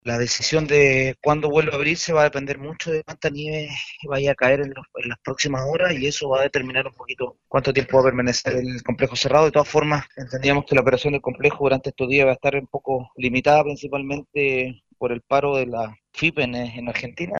El gobernador de la provincia de Los Andes, Sergio Salazar, comentó que el cierre se determinó a las 08:00 horas y la opción de reabrir el complejo dependerá de cuánta nieve caiga en el lugar.